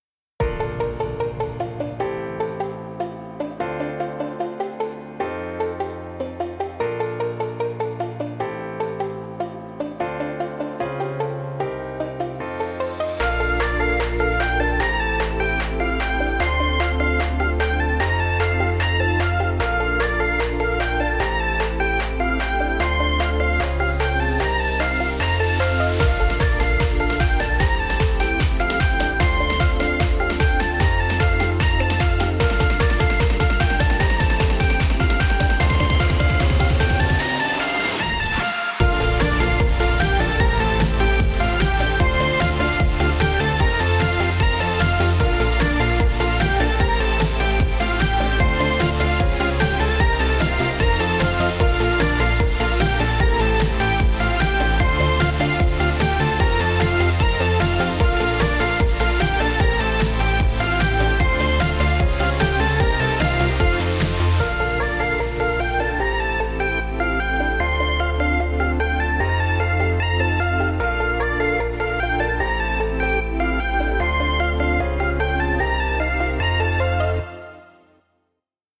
明るい/EDM/かわいい/コミカル/ポップ
【耐久版あり】明るい雰囲気のかわいいEDMっぽいBGMです。